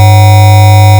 Mystery Signal Audio File
So, the two basic types of signals were summed to create this mystery signal are
lab03_mystery_signal.wav